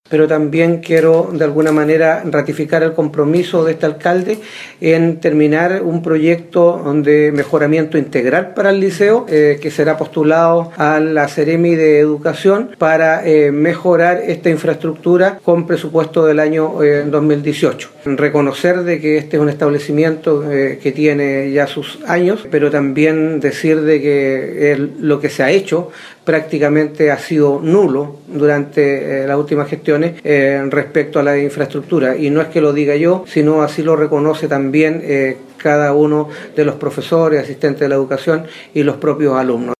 El alcalde de Ancud Carlos Gómez sostuvo que se atenderán estas falencias más urgentes, pero al mismo tiempo se trabajará en un proyecto definitivo que atienda, con una iniciativa con presupuesto regional, la reparación integral del edificio.